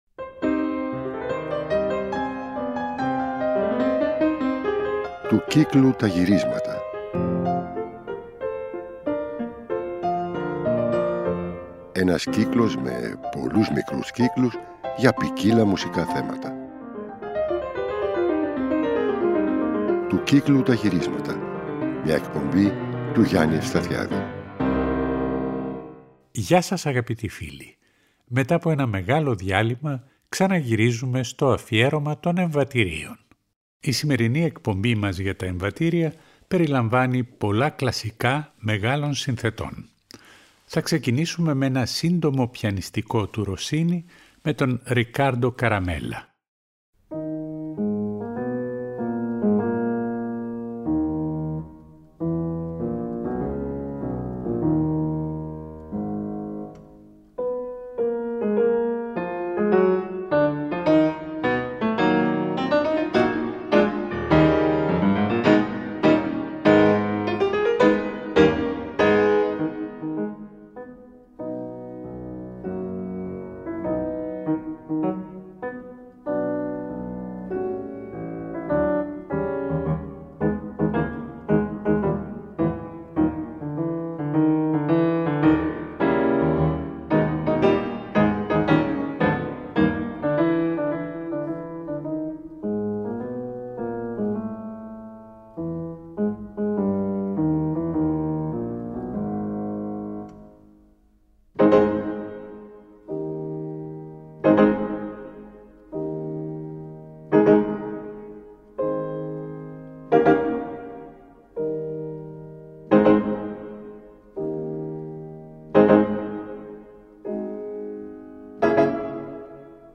Σ΄ αυτή την εκπομπή περιλαμβάνονται πολλά κλασικά εμβατήρια μεγάλων συνθετών.
Επίσης ένα κολλάζ από εμβατήρια που γράφτηκαν για το τσίρκο!